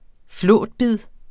Udtale [ ˈflɔˀdˌbið ]